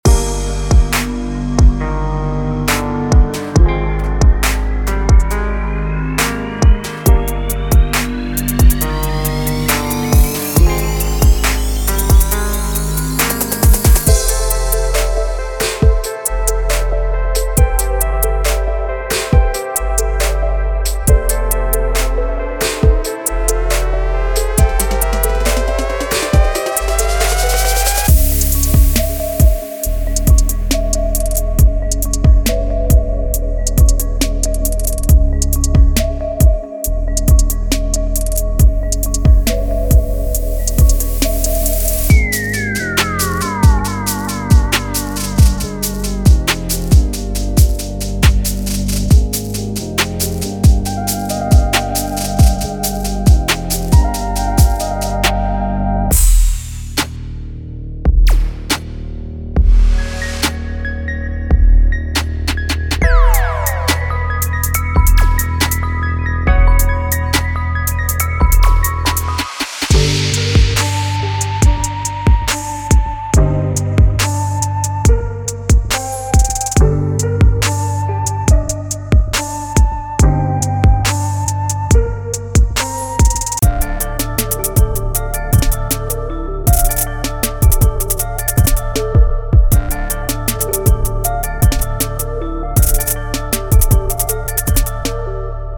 Hip-Hop / R&B Trap